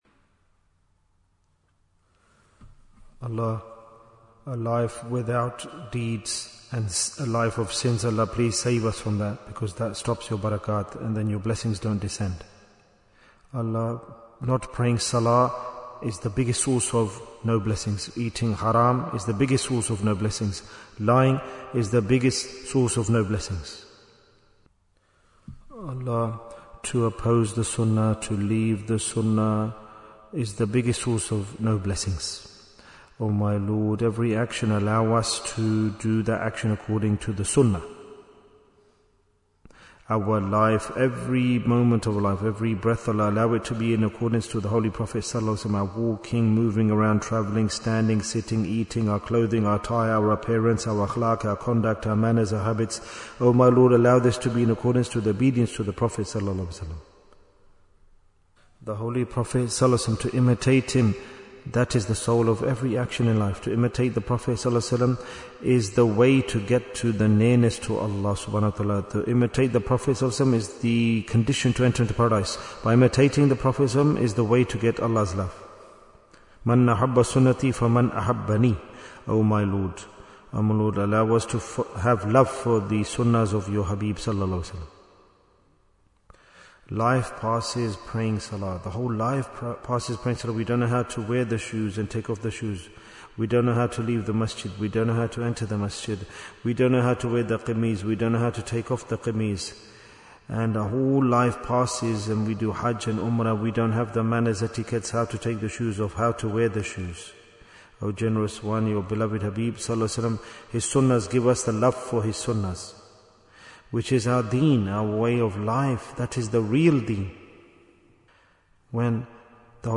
Why is Tazkiyyah Important? - Part 22 Bayan, 20 minutes24th March, 2026